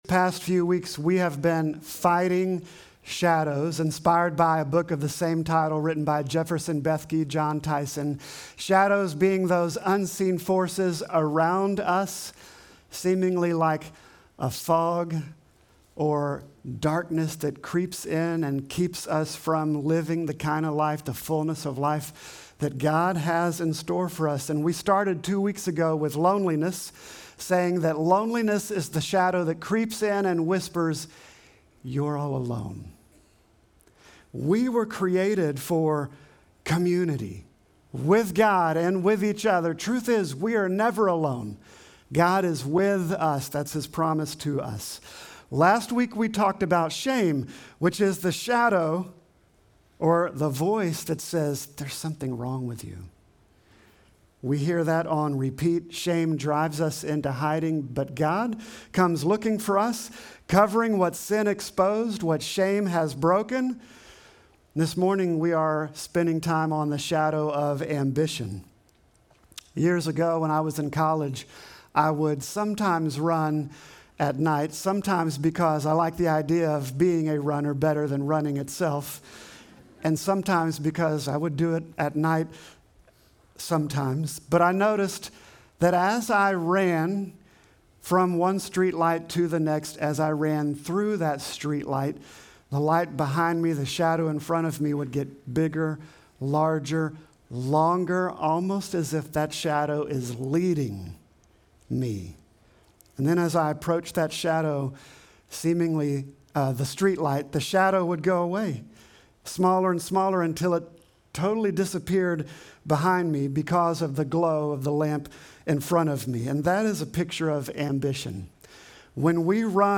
Sermon text: Mark 10:37